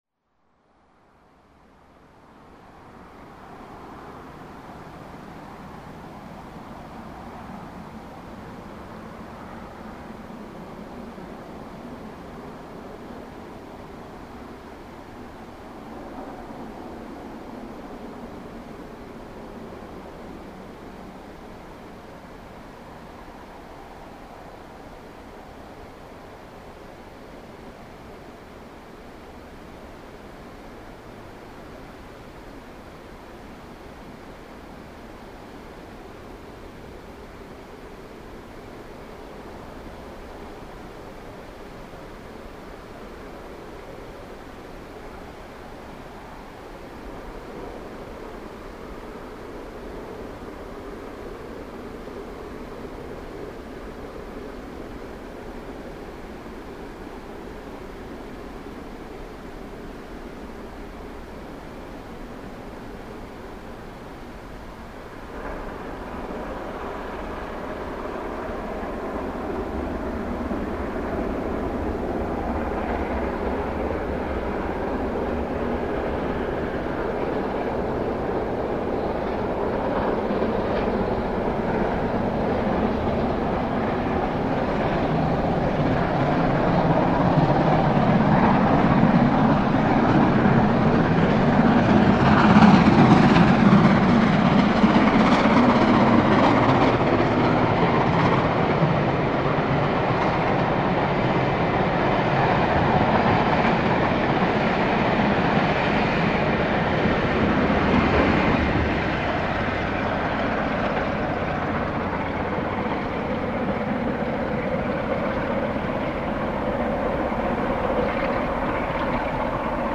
After searching around in the dark and giving up on one very  wet field, we walked along the old canal and set up in a slightly dryer spot just in time to hear 5690  working well upgrade towards Hellifield with the sounds of a nearby river for accompaniament.